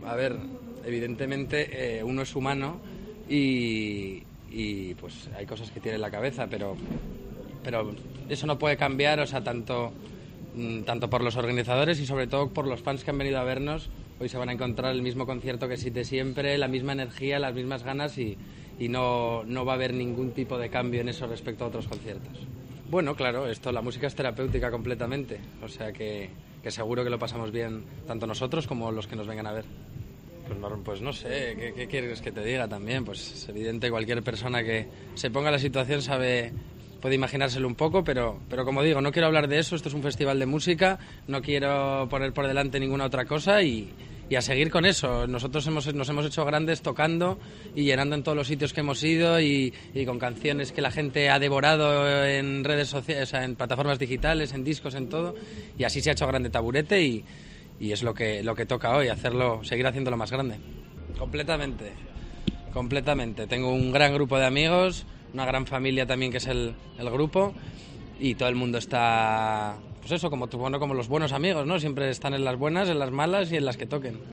El hijo de Luis Bárcenas, Willy Bárcenas, habla sobre la sentencia de la Gürtel, antes de actuar en Asturias